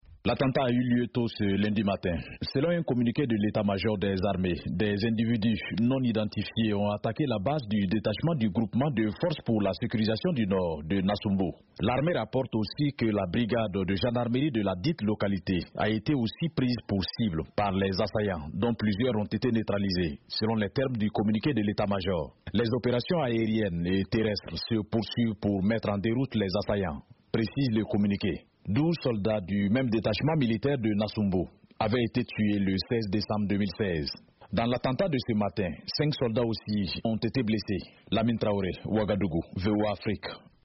De Ouagadougou la correspondance de